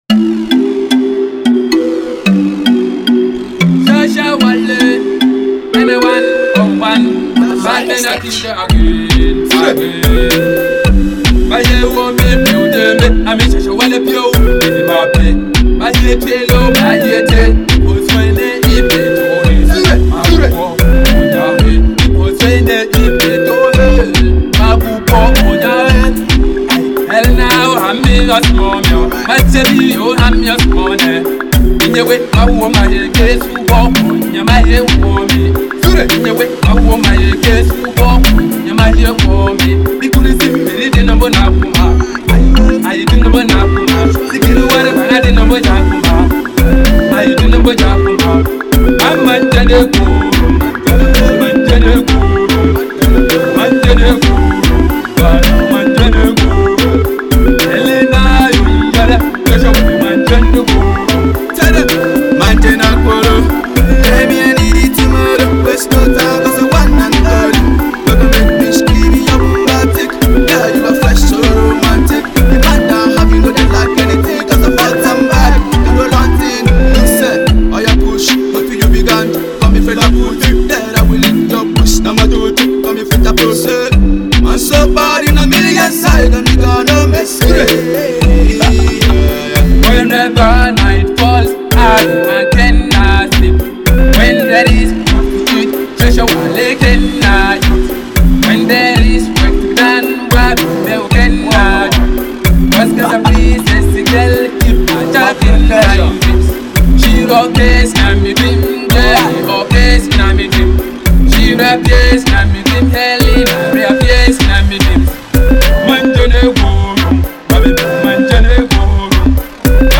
New dancehall vibe